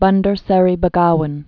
(bŭndər sĕrē bə-gäwən)